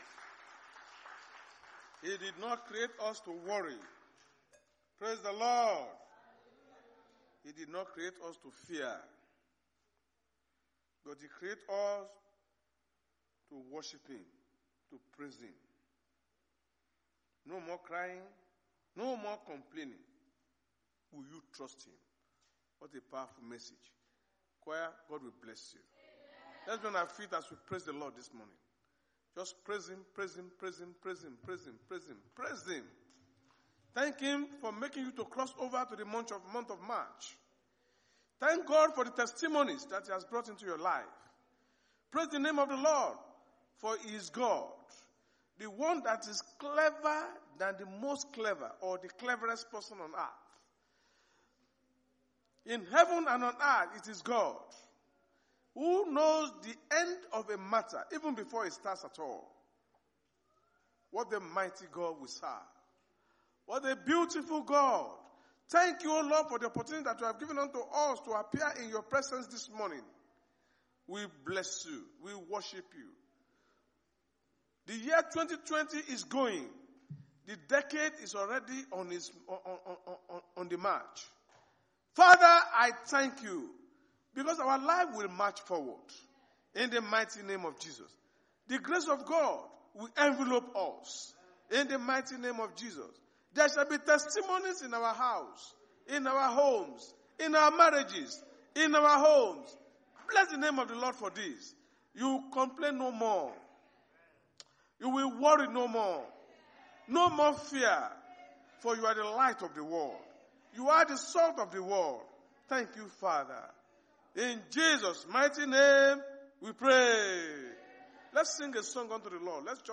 Sunday Sermon: The Power Of Light
Service Type: Sunday Church Service